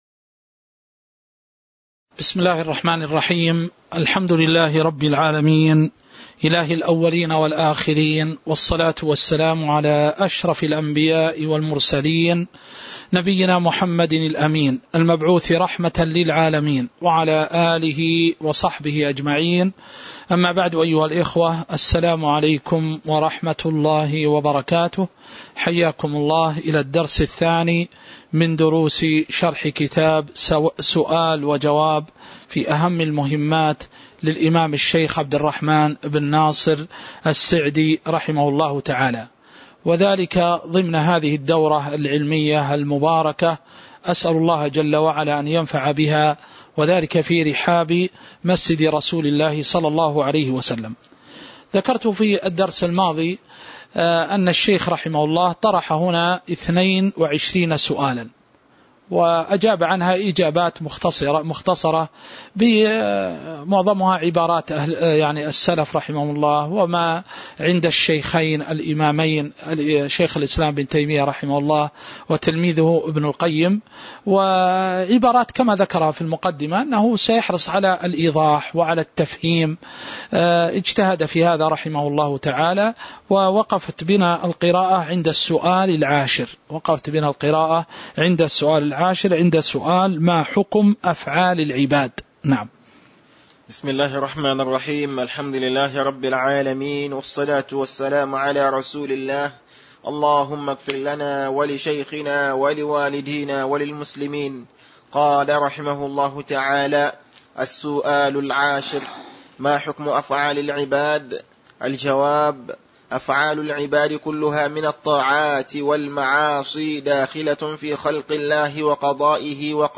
تاريخ النشر ١٧ صفر ١٤٤٢ هـ المكان: المسجد النبوي الشيخ